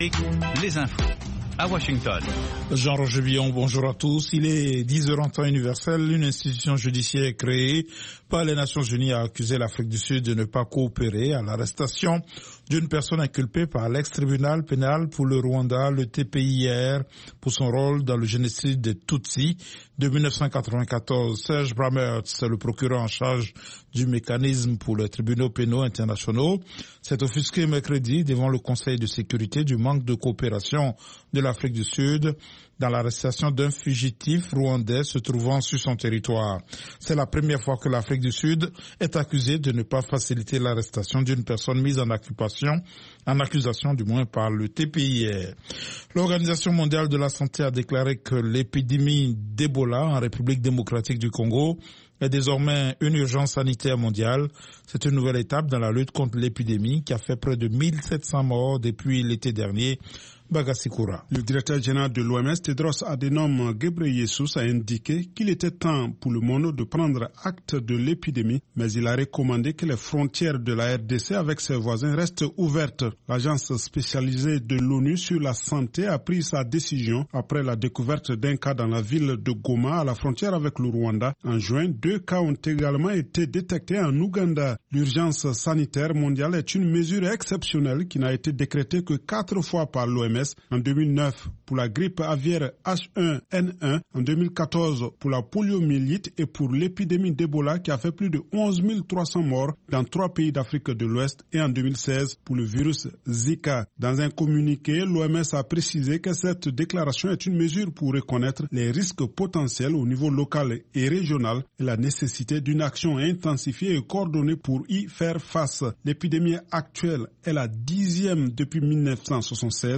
5 Minute Newscast